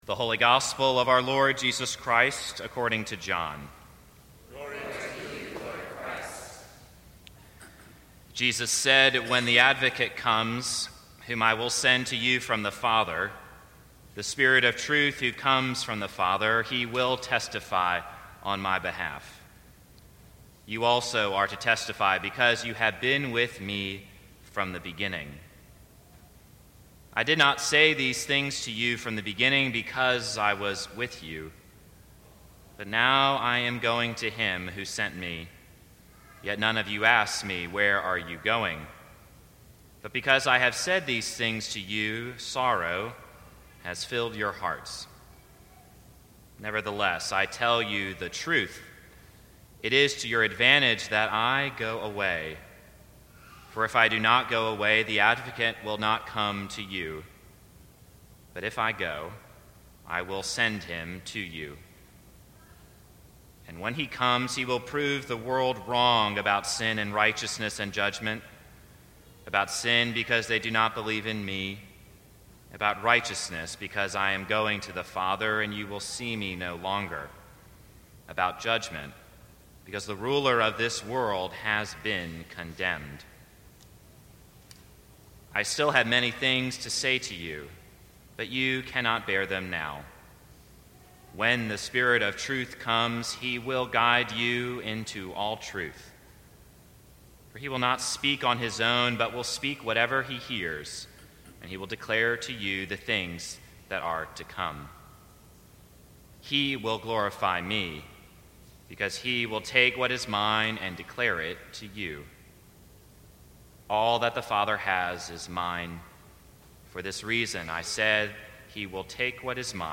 Sermons from St. Cross Episcopal Church God Breaks Down Barriers May 21 2018 | 00:12:54 1x 00:00 / 00:12:54 Subscribe Share Apple Podcasts Spotify Overcast RSS Feed Share Link Embed